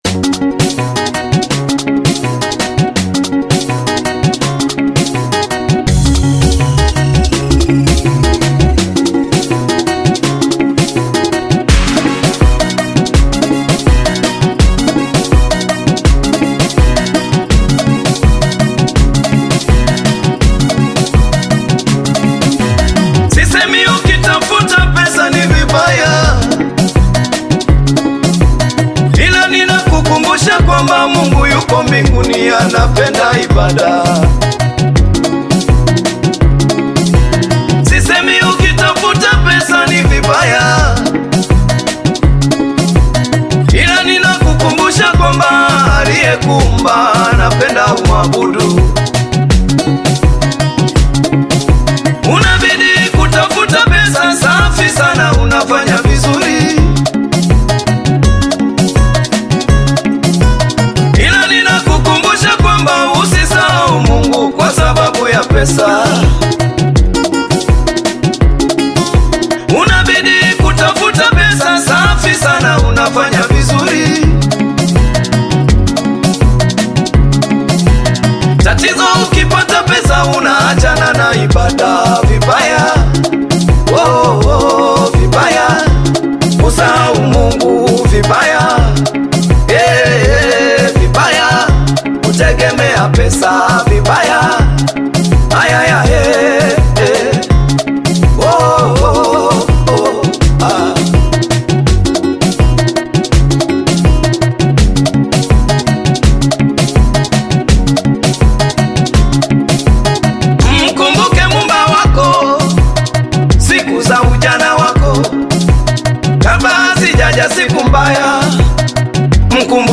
Gospel music track
Tanzanian gospel artist, singer, and songwriter